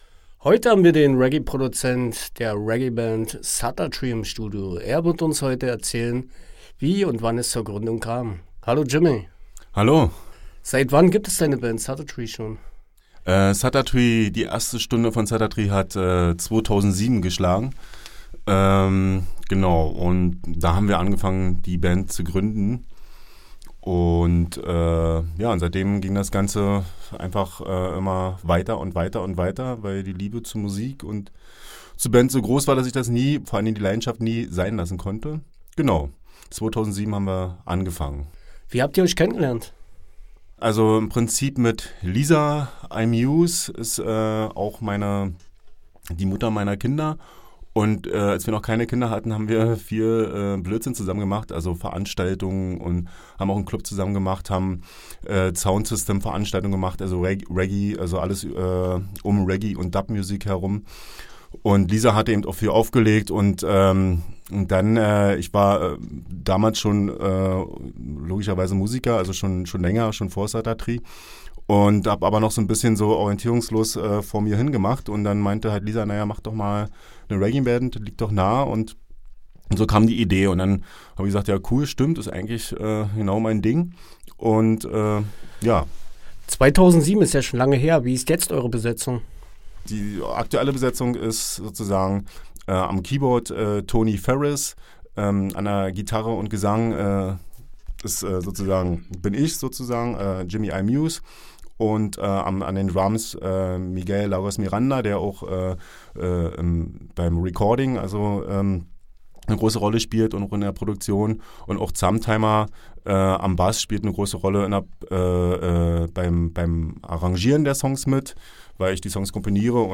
Roots-Reggae-Dub-Soul-Afro-Music – Nigeria, Togo, Chile, Italien.
Gegründet 2007 hat sich die multikulti Band SattaTree zu einer Institution in der Berliner Reggae-Szene entwickelt.